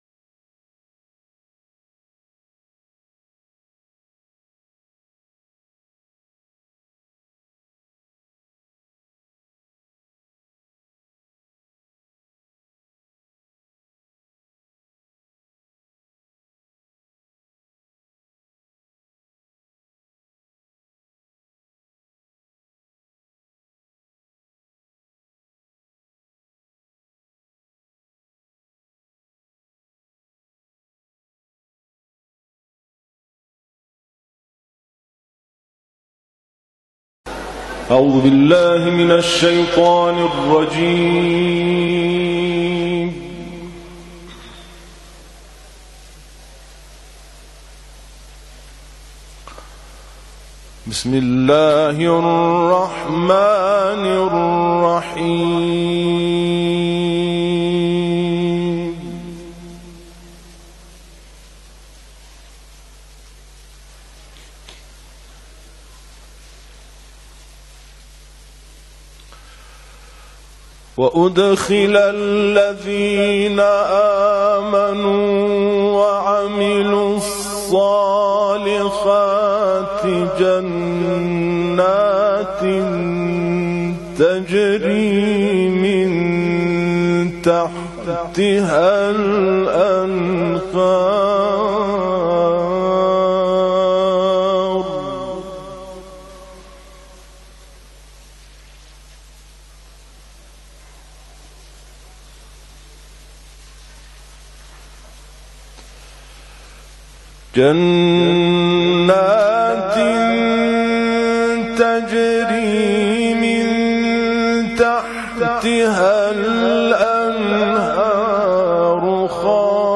Таляват